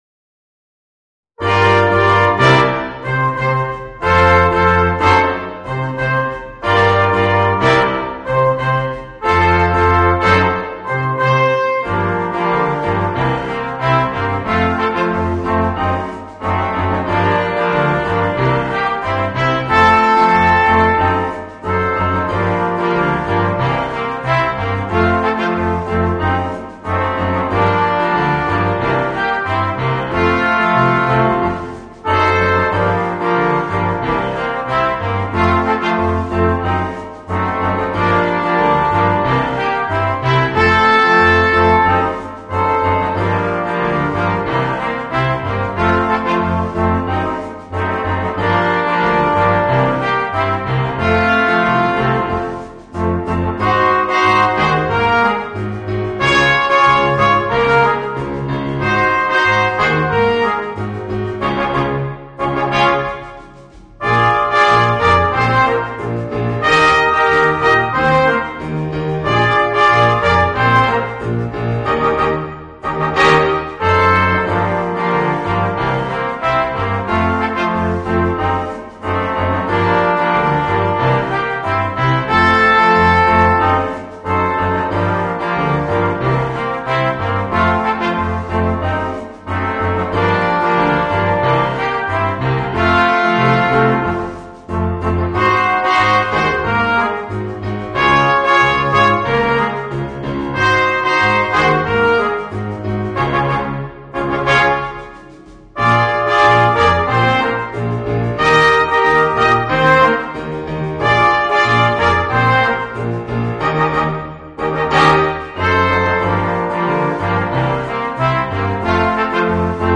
Voicing: 2 Trumpets, Horn, Trombone, Tuba and Drums